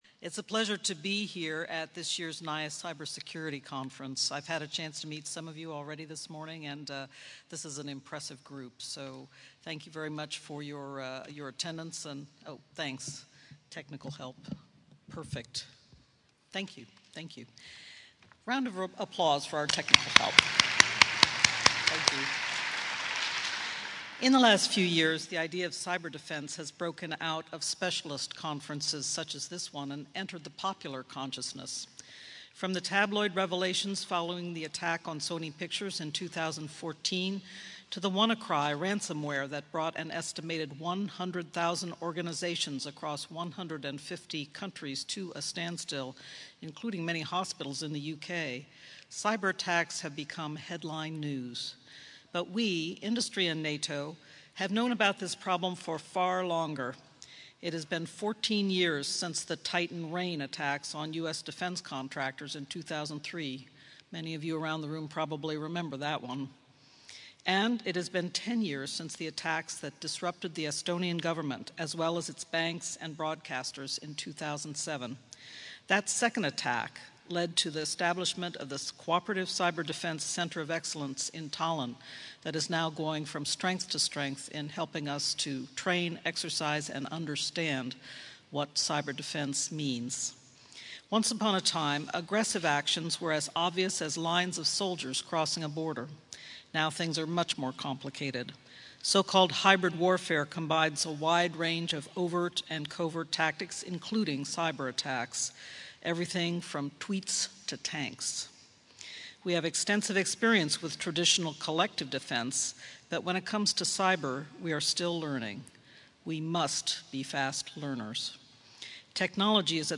NATO Deputy Secretary General, Rose Gottemoeller, spoke of the vital importance of cyber defence when she addressed industry experts at the NATO Information Assurance Symposium (NIAS) Cyber Conference today (19 October 2017) in Mons, Belgium.